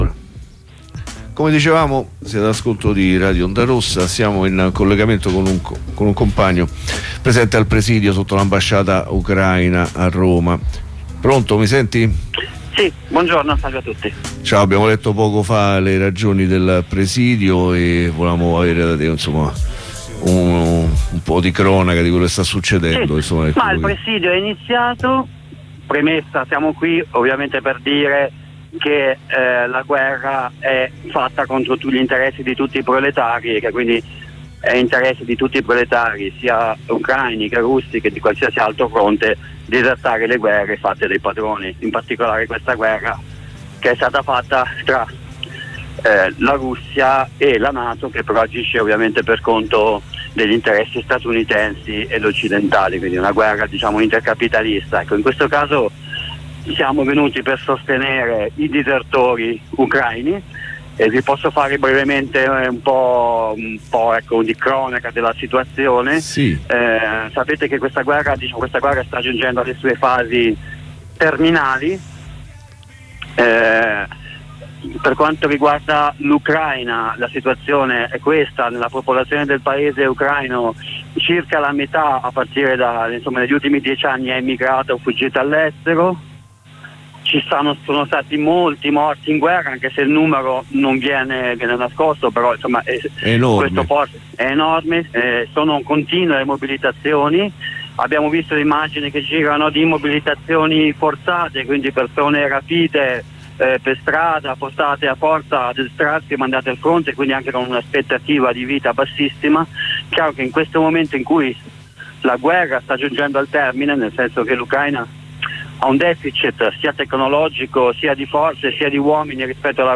Dal presidio di Roma